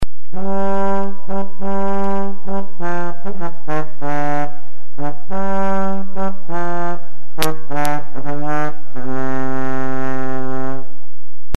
Variously spelt "sackbut" or "sagbut", it is basically a slide trumpet which allows the player to alter the length of the sound tube as he/she plays. As the sound tube is extended, the notes get lower.
Sackbutt Sound Clips